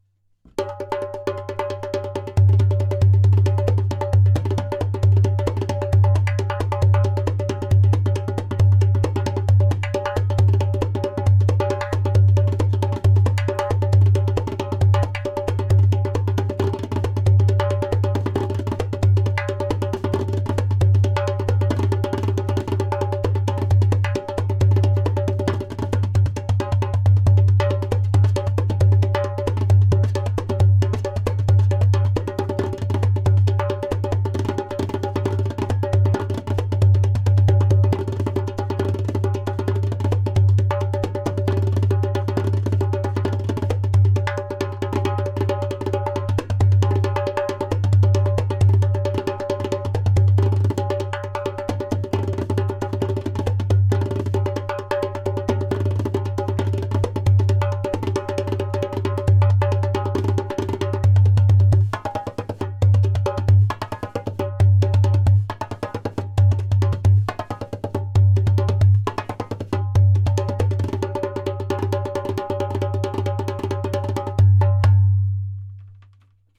130 bpm:
• Taks with harmonious overtones.
• Deep bass for a solo darbuka.
• Loud clay kik/click sound for a solo darbuka!